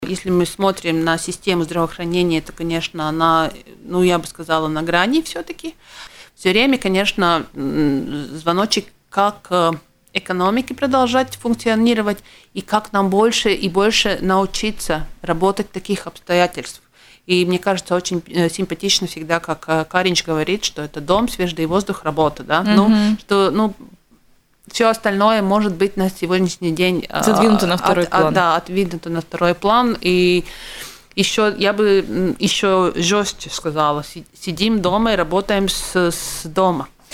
Система здравоохранения Латвии находится на грани, и каждый житель страны несет ответственность за состояние своего здоровья. Об этом в интервью радио Baltkom заявила экс-министр здравоохранения, депутат Сейма Анда Чакша.